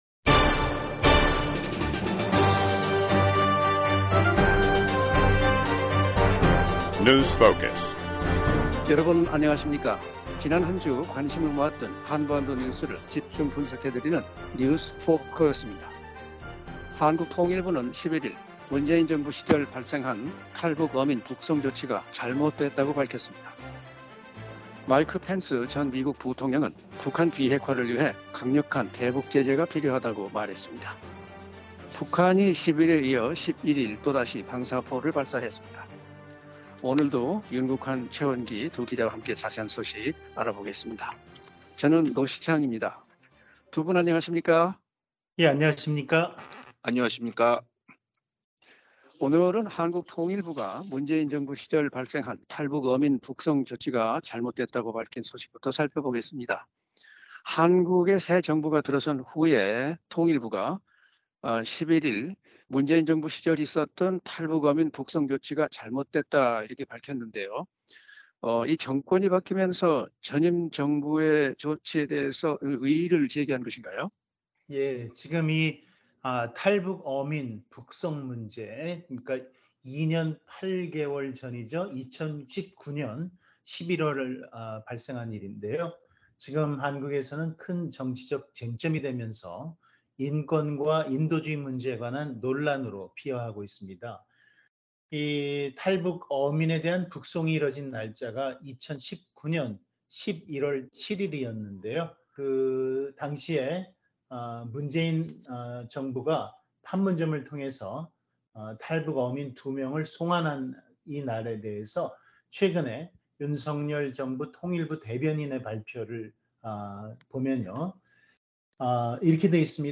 지난 한 주 관심을 모았던 한반도 뉴스를 집중 분석해 드리는 ‘뉴스 포커스’입니다. 한국 통일부는 11일 문재인 정부 시절 발생한 ‘탈북 어민 북송’ 조치가 잘못됐다고 밝혔습니다. 마이크 펜스 전 미국 부통령은 북한 비핵화를 위해 강력한 대북 제재가 필요하다고 말했습니다. 북한이 10일에 이어 11일 또다시 방사포를 발사했습니다.